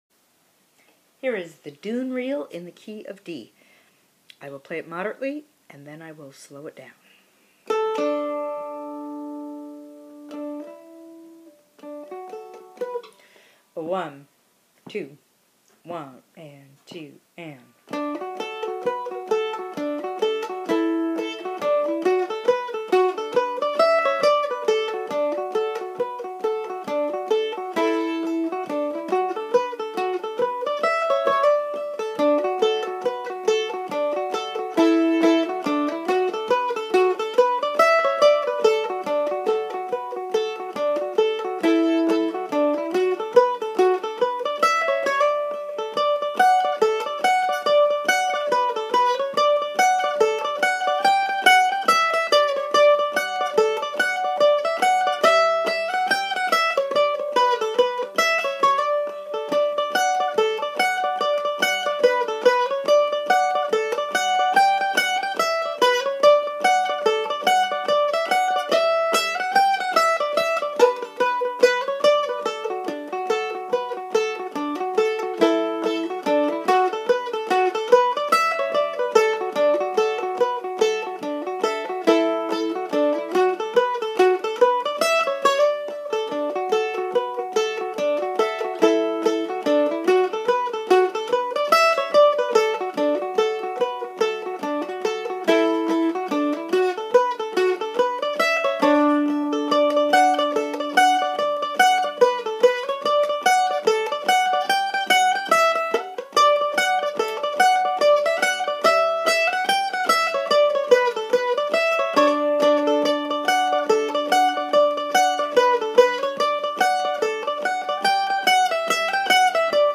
I feel in love with the tune and the way she plays that tune.
Day-1-Reel_-The-Doon-Reel.mp3